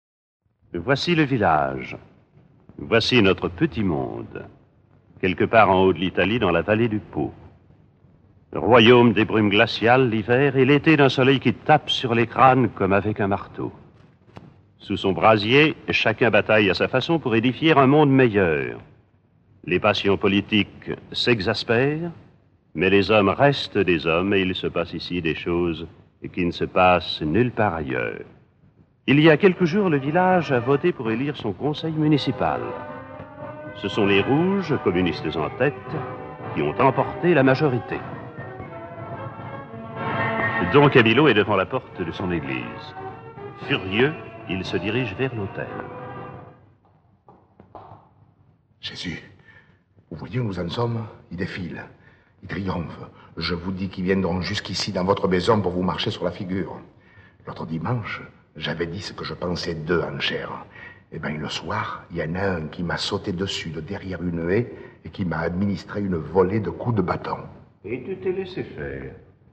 Enregistrement original (extraits)